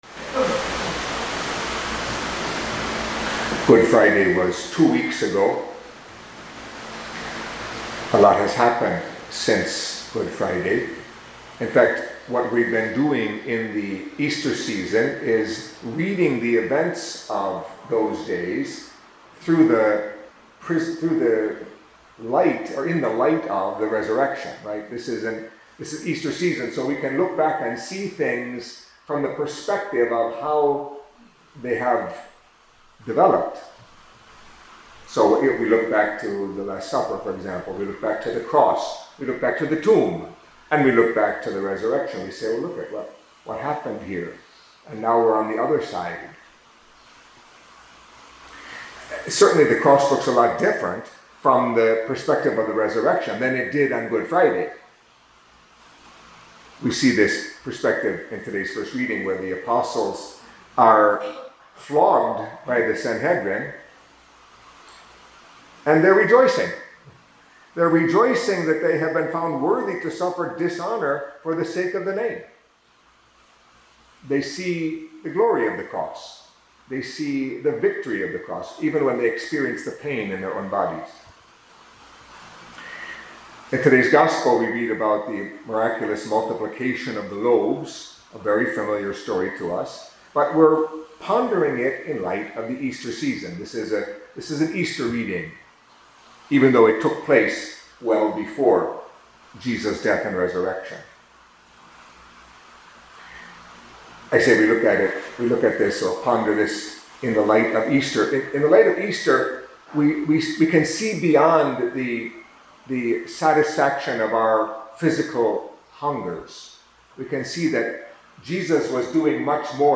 Catholic Mass homily for Friday in the Second Week of Easter